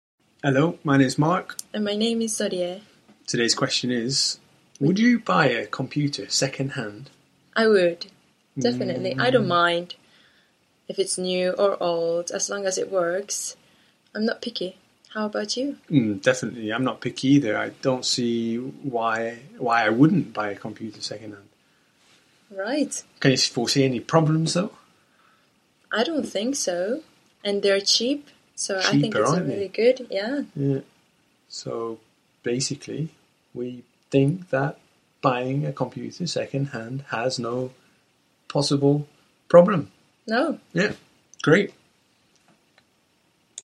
实战口语情景对话 第1382期:Would you buy a computer secondhand?